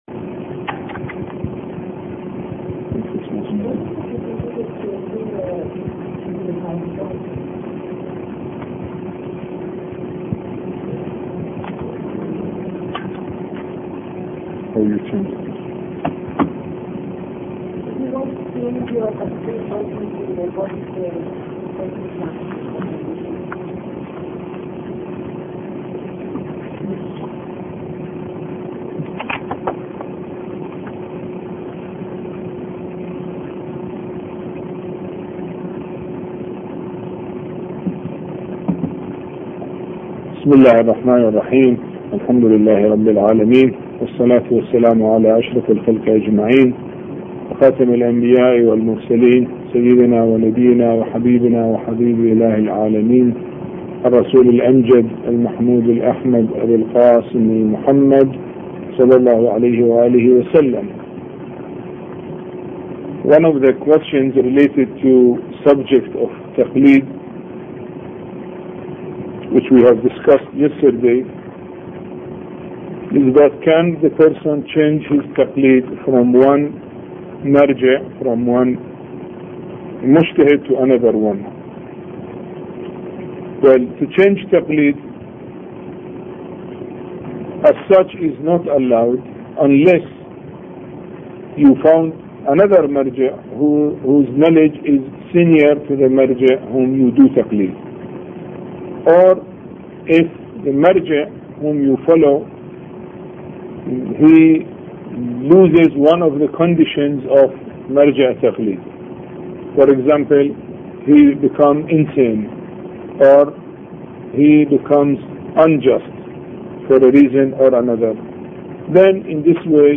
A Course on Fiqh Lecture 1